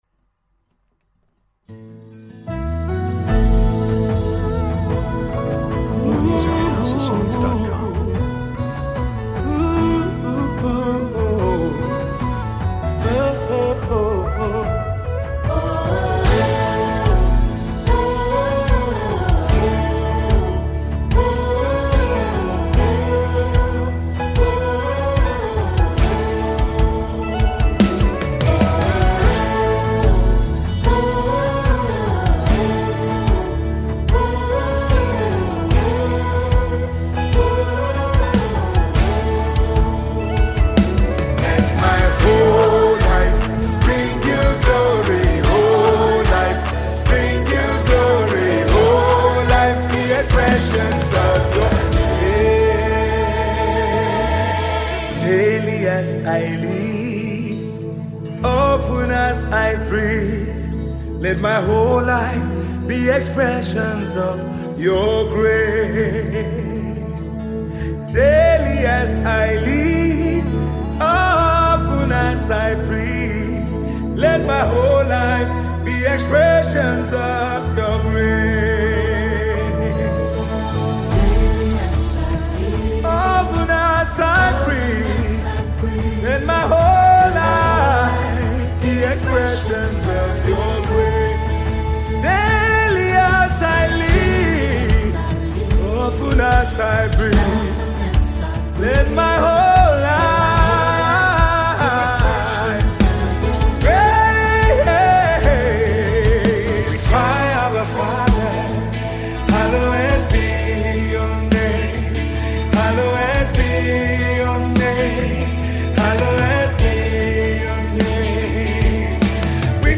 Tiv songs